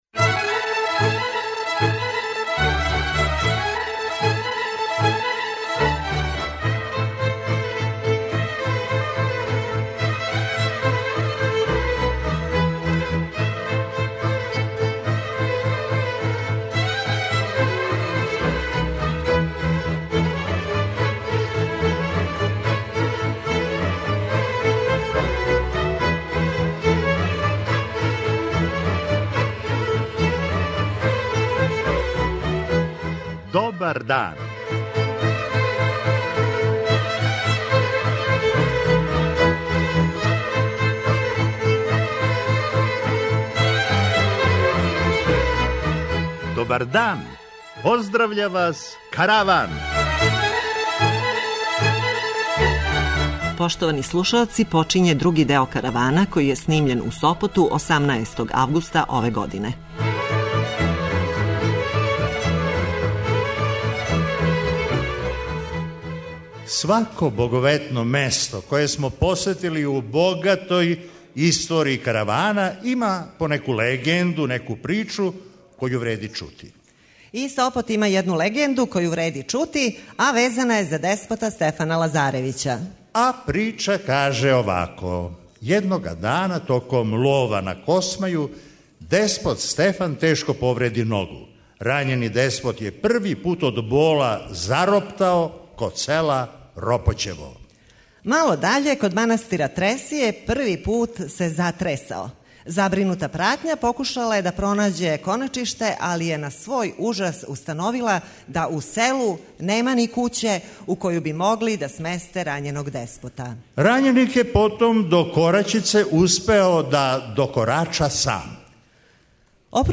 Још једно специјално издање Каравана, други део. Овога пута житељи Сопота имали су прилику да буду део јединственог и непоновљивог догађаја - присуствовали су јавном снимању Каравана, а ви сте данас у прилици да чујете управо тај сопотски Караван!
преузми : 24.21 MB Караван Autor: Забавна редакција Радио Бeограда 1 Караван се креће ка својој дестинацији већ више од 50 година, увек добро натоварен актуелним хумором и изворним народним песмама.